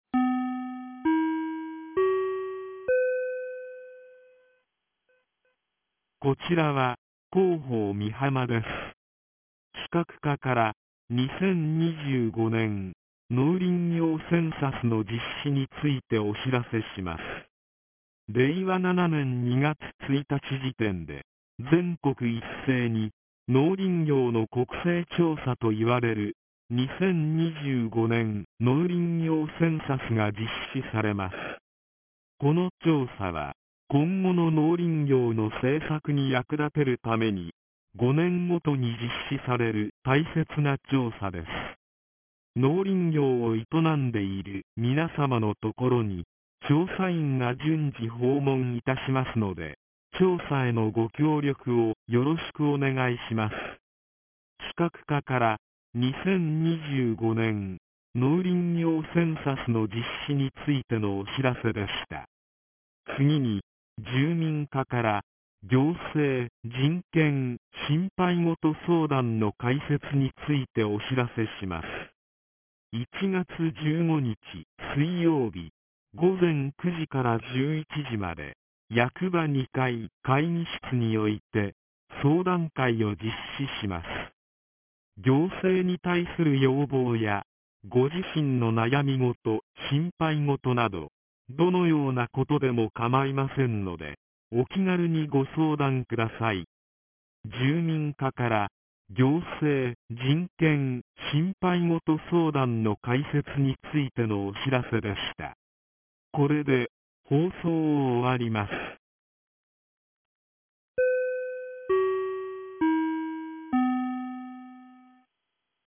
■防災行政無線情報■
放送音声